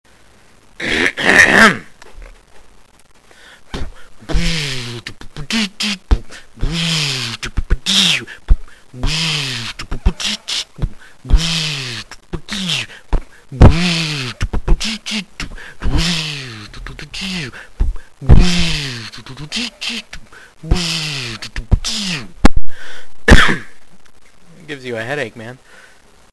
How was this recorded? I was at work and used a pair of headphones to record this.